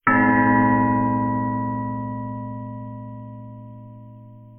bells_buddhist_chime.wav